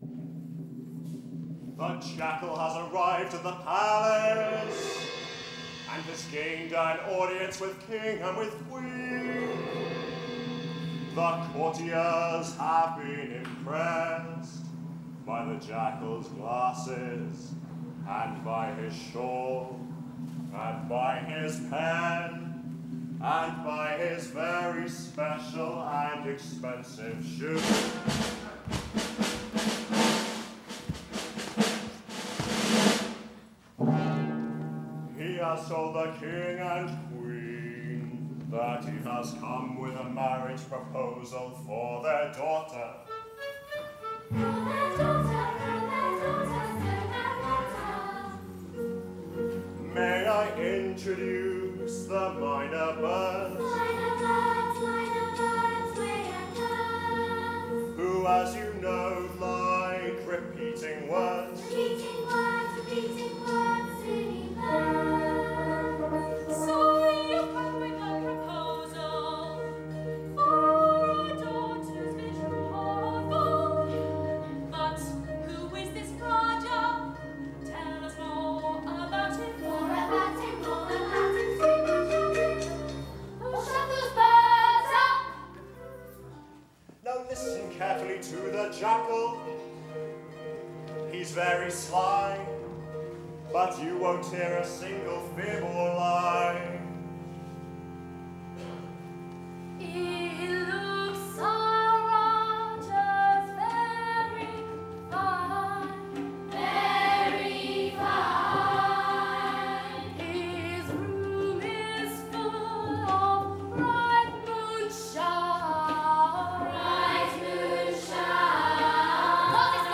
The Marriage Proposal (Story Teller, Jackal, King, Queen, The Five Children, Myna Birds)